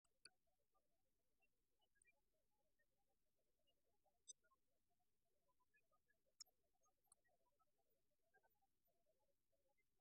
Die Rufe:
Typische Rufe des Goldhähnchen-Laubsänger aus Ussurien als mp3